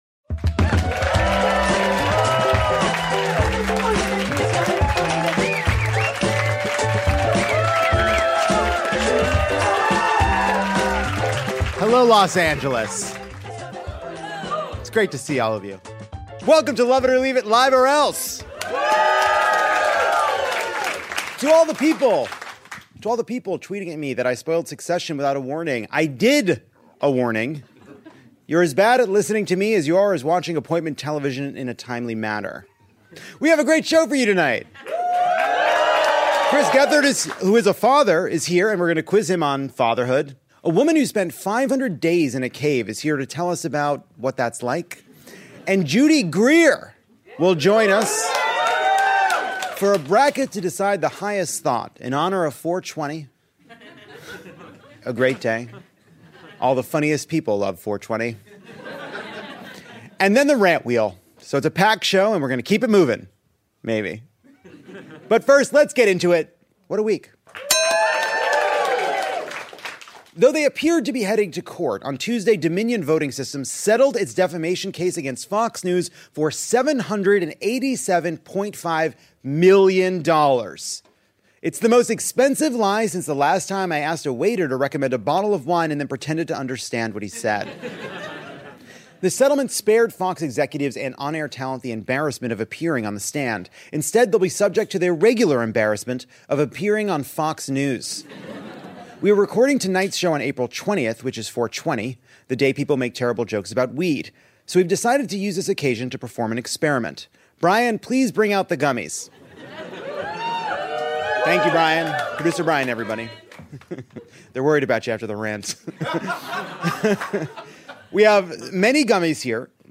Lovett Or Leave It sparks up a very special episode at Los Angeles’ Dynasty Typewriter.